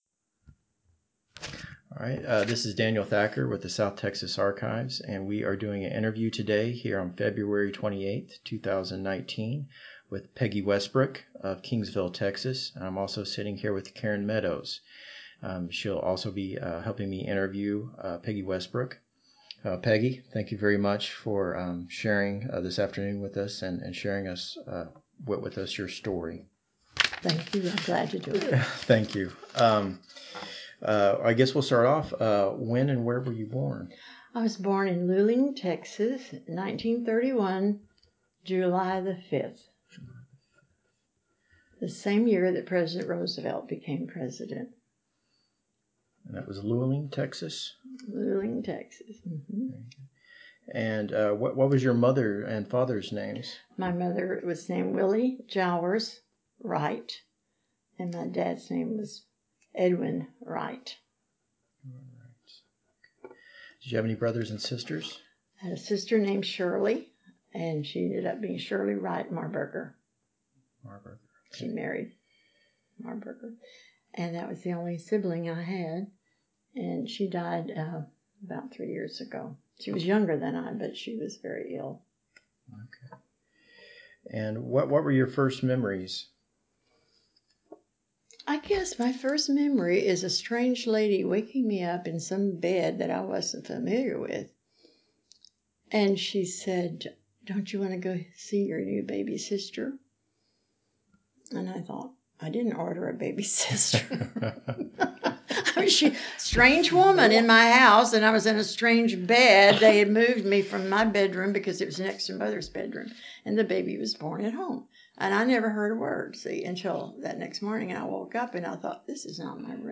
An oral history interview